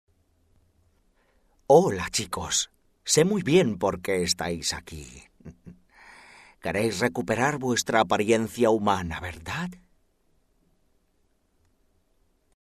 Voice talent for video games